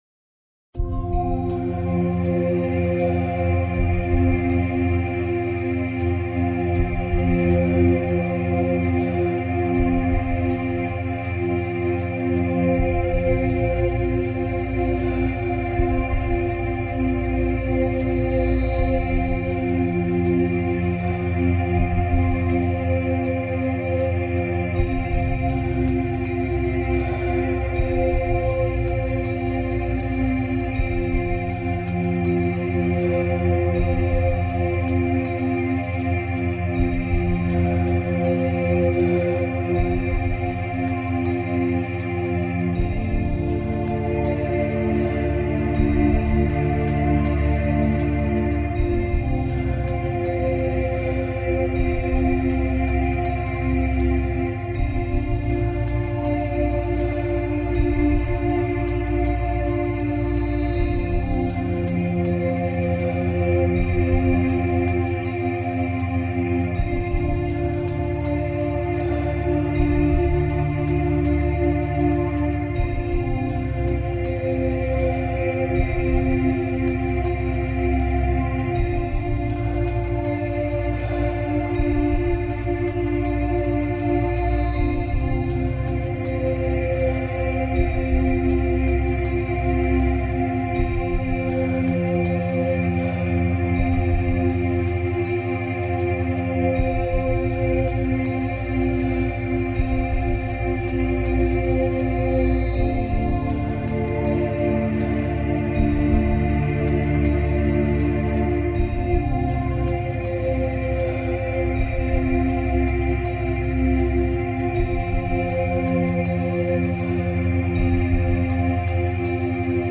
Можно включит спокойную, расслабляющую музыку.
Otlichnaya-muzyka-dlya-editatsii-mp3cut.net_.mp3